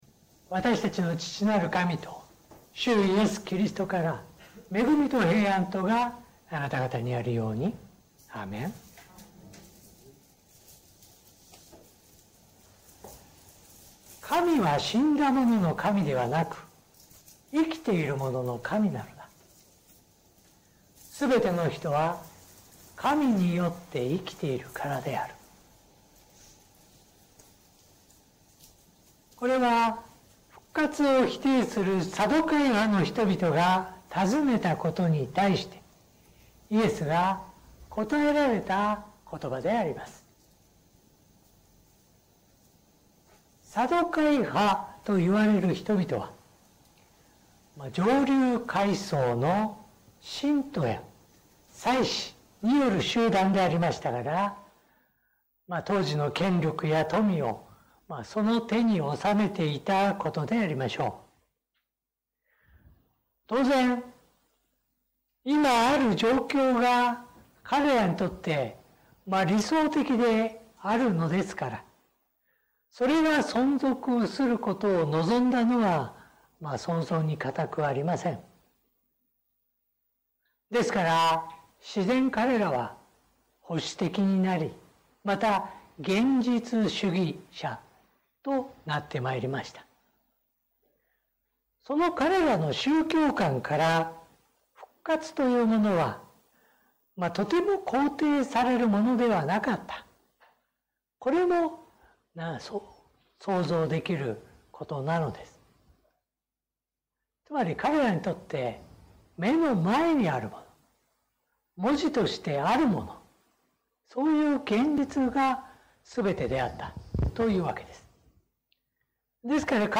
説教音声 最近の投稿 2026年3月22日 礼拝・四旬節第5主日 3月22日 「死んでも生きる？」